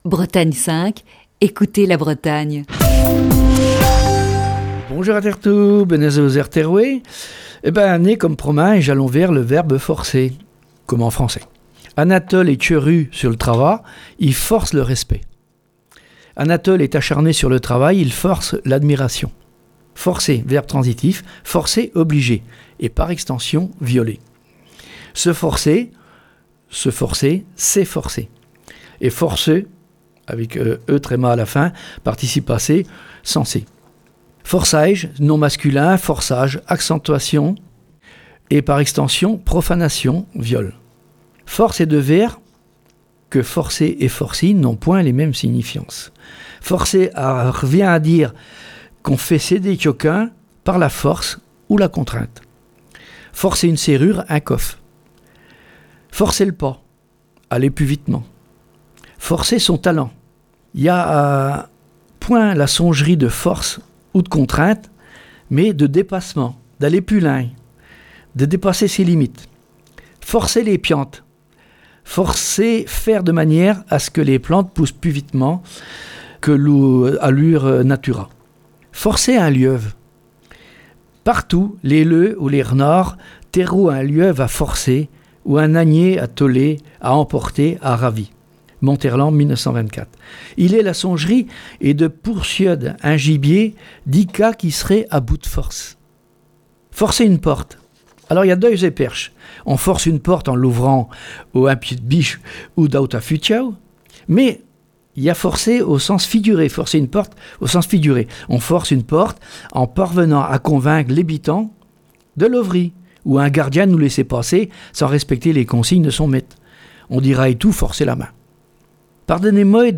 Chronique du 23 mars 2021.